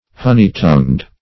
Meaning of honey-tongued. honey-tongued synonyms, pronunciation, spelling and more from Free Dictionary.